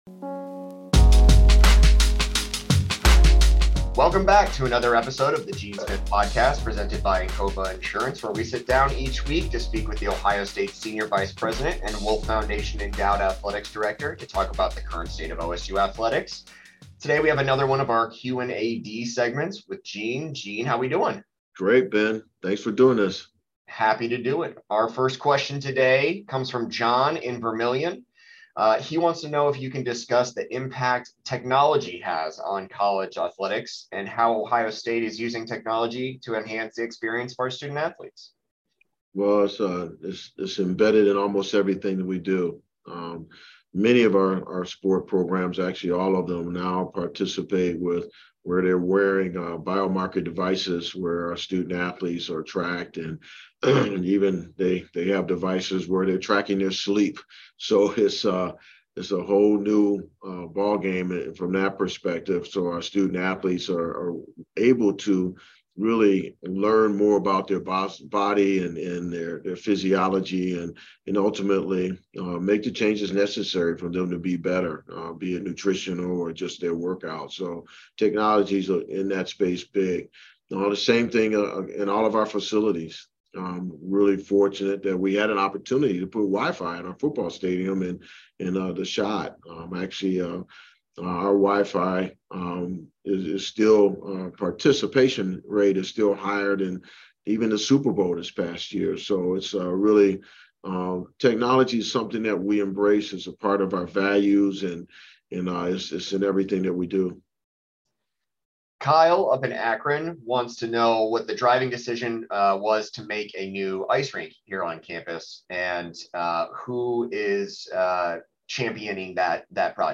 In his Q&AD segment, Gene Smith fields listener questions about the role of technology in the lives of Ohio State student-athletes, the decision to build a new ice rink at Ohio State, and more.